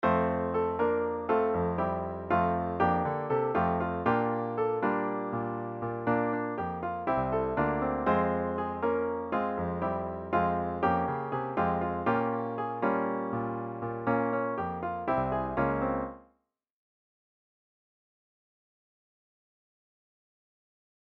1) Example 1 is an 8-bar melody using mainly I, IV and V chords as harmony. The first 4 bars are in F major, and the next 4 bars are in F minor. You’ll hear that the result is quite abrupt, but if you’re looking for something a bit startling, it may have a use in your song.